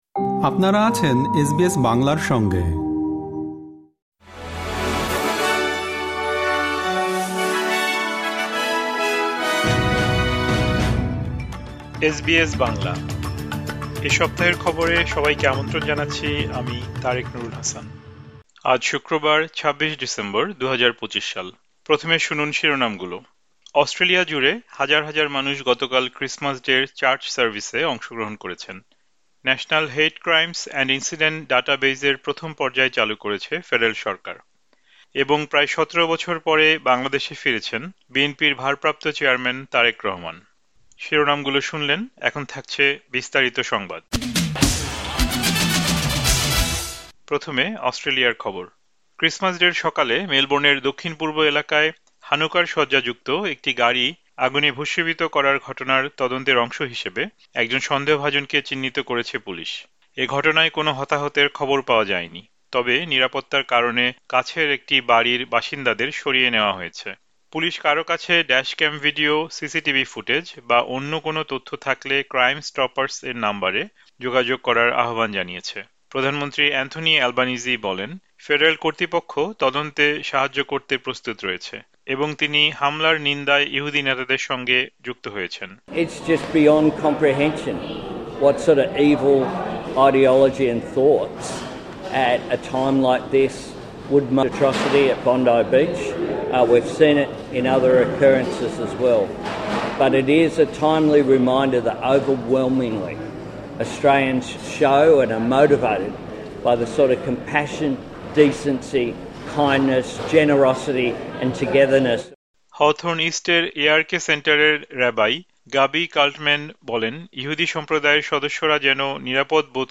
আজ শুক্রবার, ২৬ ডিসেম্বরে প্রচারিত অস্ট্রেলিয়ার এ সপ্তাহের জাতীয় ও আন্তর্জাতিক গুরুত্বপূর্ণ সংবাদ শুনতে উপরের অডিও-প্লেয়ারটিতে ক্লিক করুন।